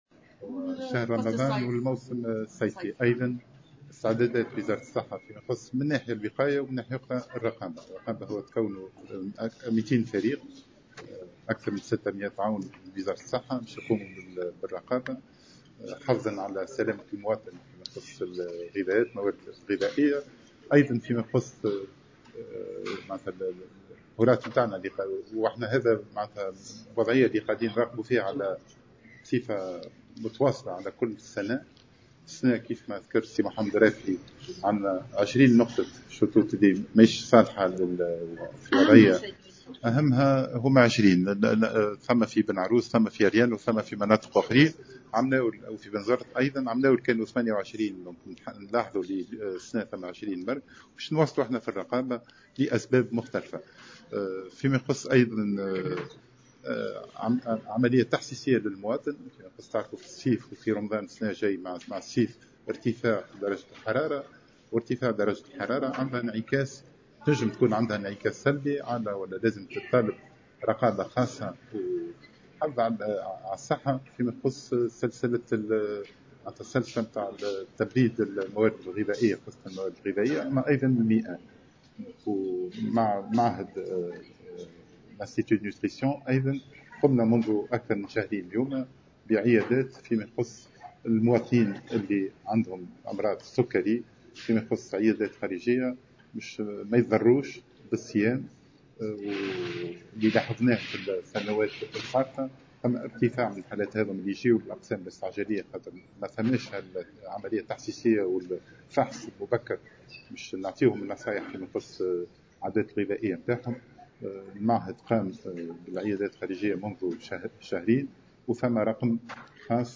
أعلن وزير الصحة العمومية سعيد العايدي في تصريح للجوهرة أف أم، خلال لقاء إعلامي...